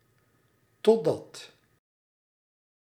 Ääntäminen
IPA: /dɔ/